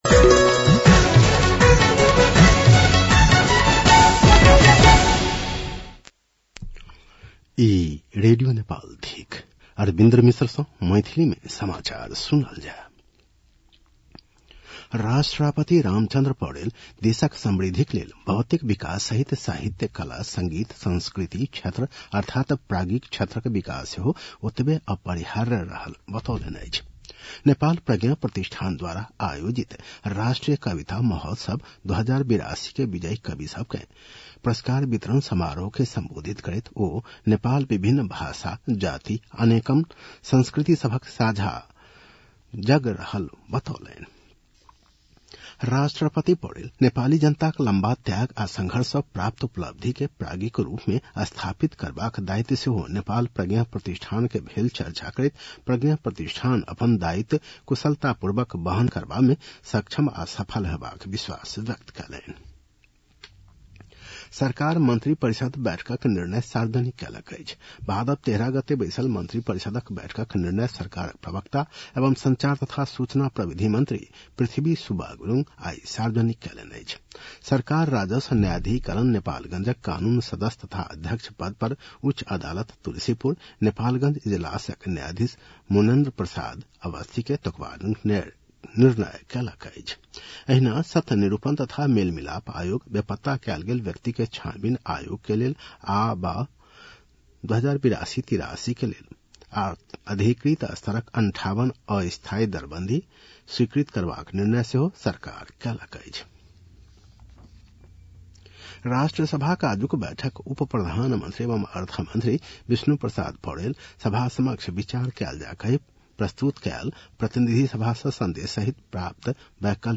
मैथिली भाषामा समाचार : १६ भदौ , २०८२
6.-pm-maithali-news-.mp3